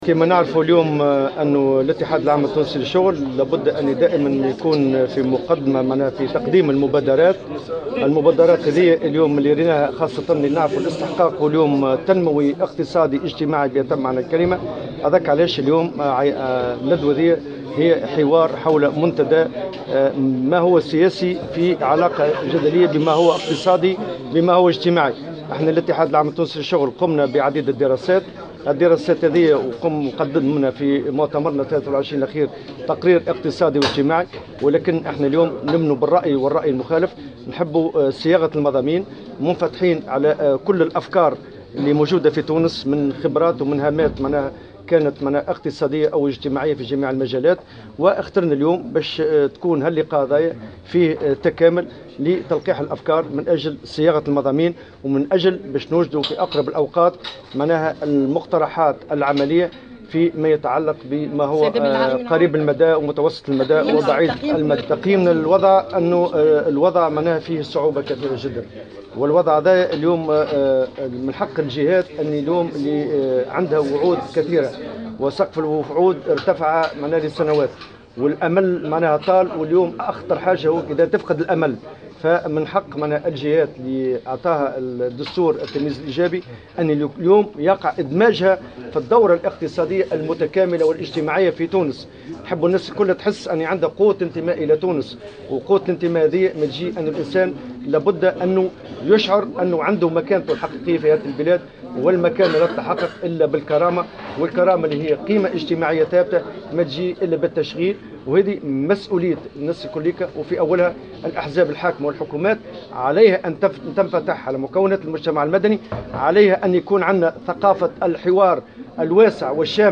أكد الأمين العام لاتحاد الشغل نورالدين الطبوبي في تصريح لمراسلة الجوهرة "اف ام" اليوم الخميس 27 أفريل 2017 أن الندوة التي يعقدها الاتحاد اليوم تأتي للحوار حول منتدى ما هو سياسي في علاقة بما هو اقتصادي واجتماعي في ظل التحركات الإحتجاجية التي تشهدها عدة جهات في تونس.